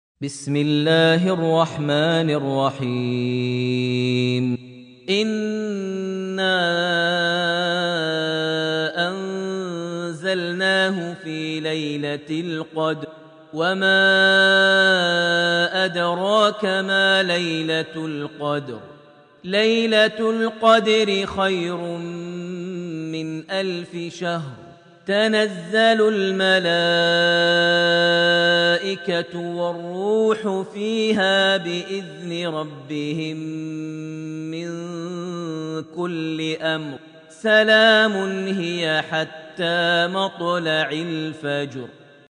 surat Al-Qadr > Almushaf > Mushaf - Maher Almuaiqly Recitations